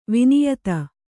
♪ viniyata